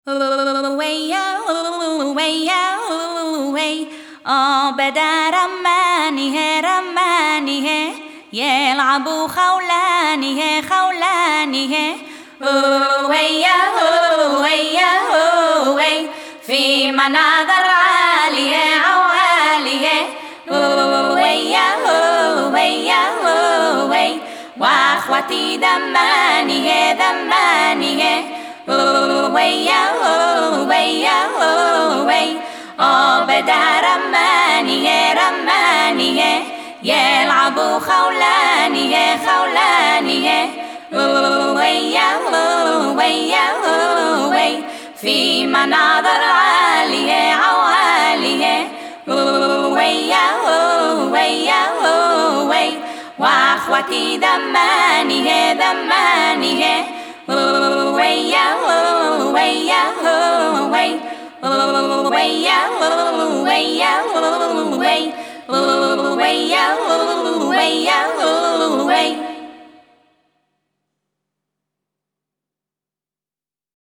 Drums
Bass
Guitar & Violin
Keytar & Boards
Genre: World